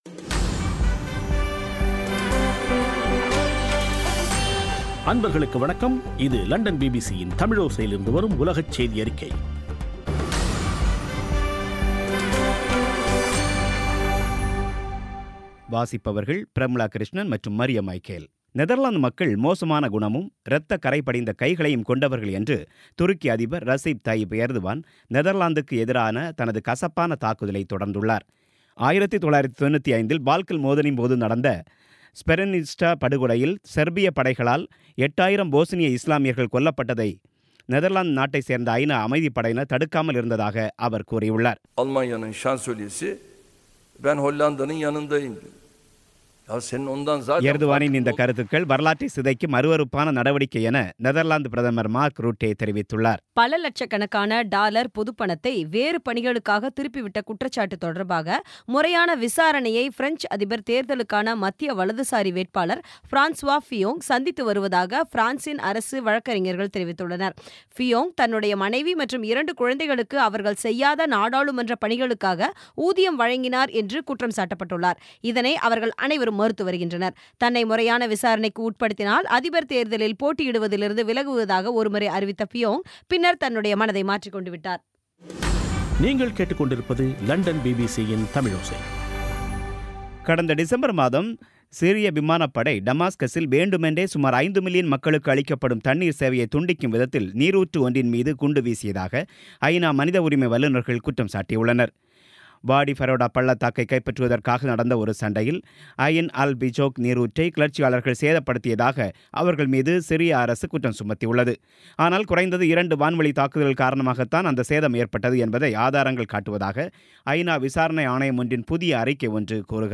பிபிசி தமிழோசை செய்தியறிக்கை (14/03/17)